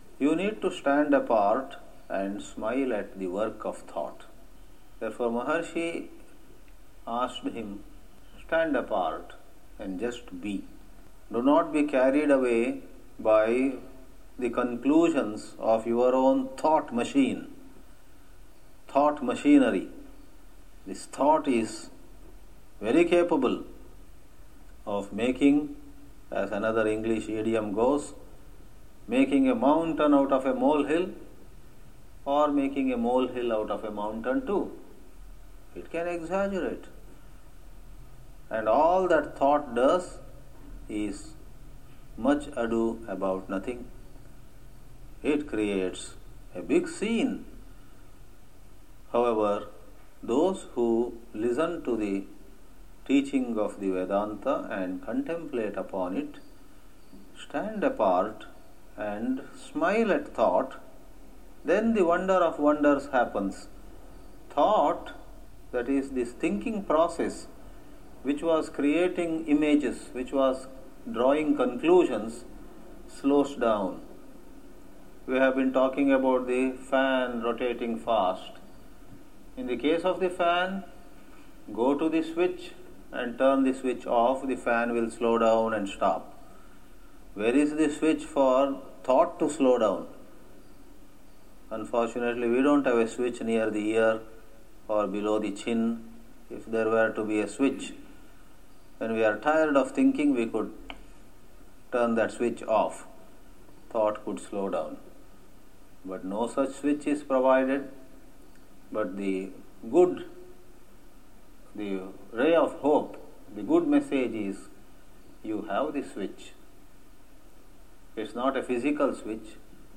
Excerpt of a lecture from India.
thought-guru-lecture.mp3